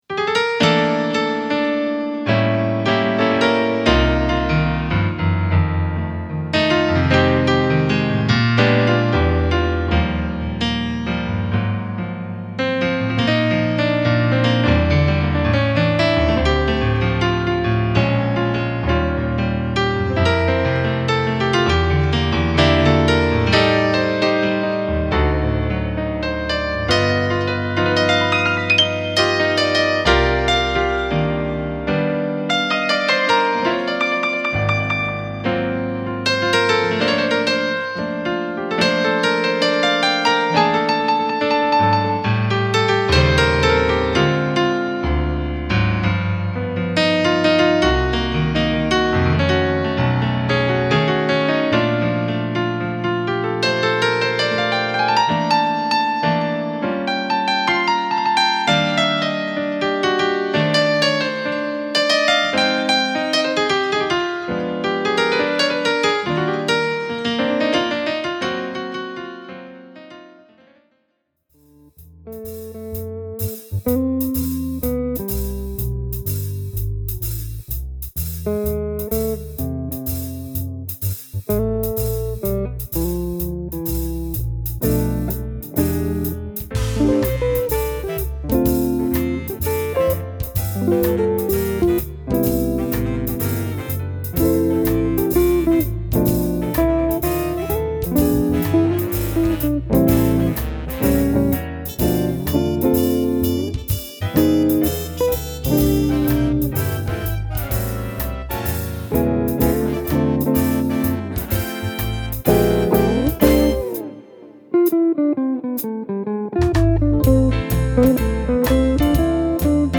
A dynamic vocalist and multi-instrumentalist